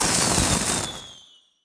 sfx_skill 13_1.wav